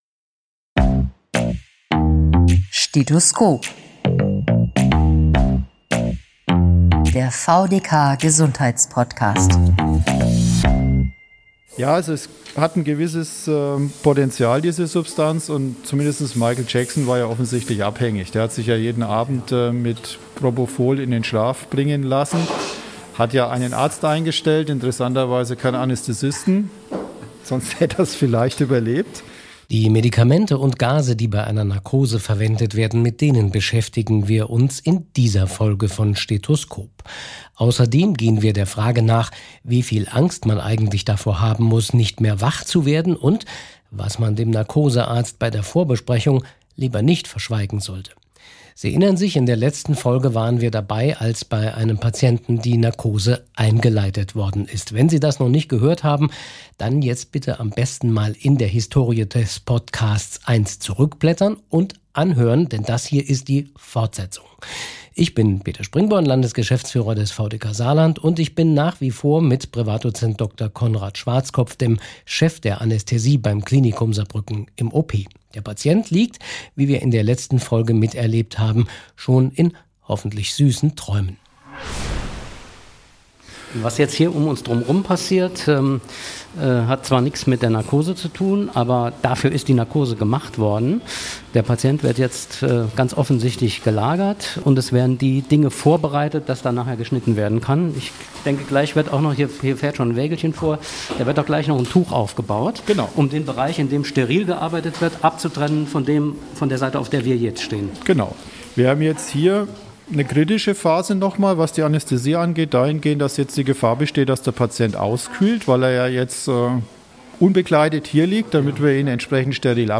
Wir sind zurück im OP. In der letzten Folge von Stethoskop haben wir erlebt, wie bei einem Patienten die Narkose eingeleitet wurde.